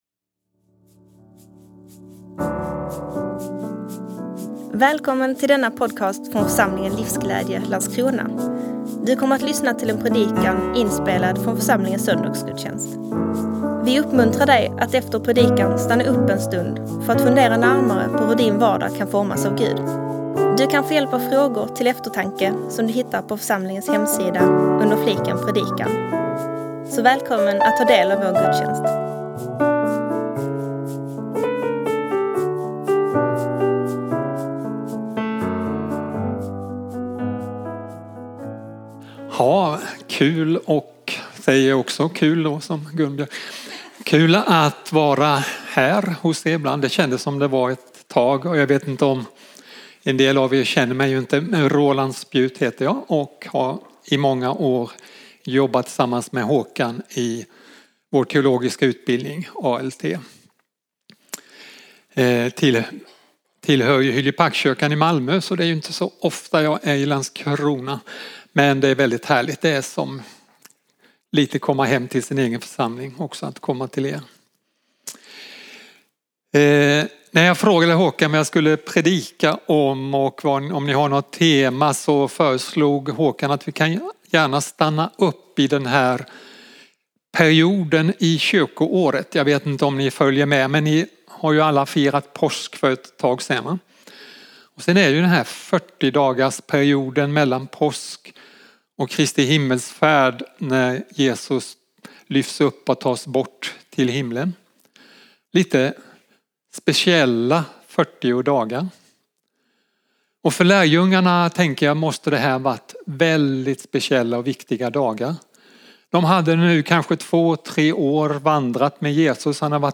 Predikan – Livsglädje